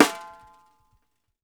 SNARESOFF -L.wav